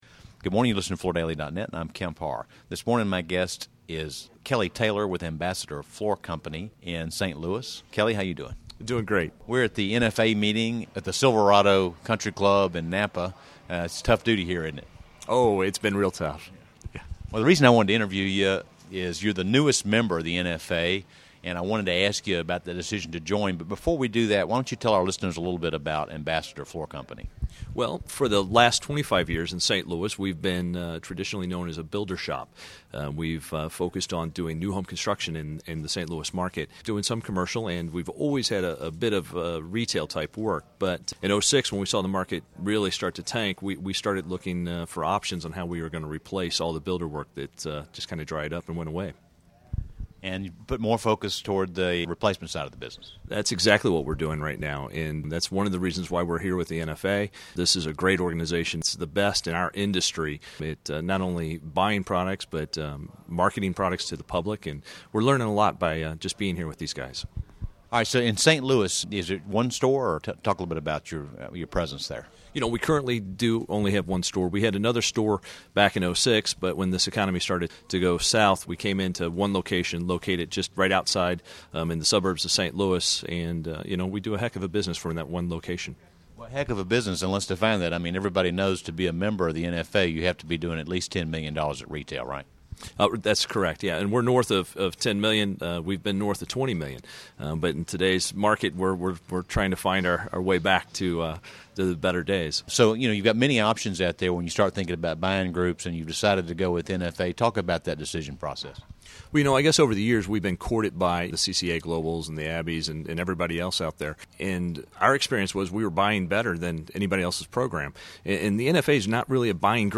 Listen to the interview to hear more about this retailer's business and business conditions in St. Louis.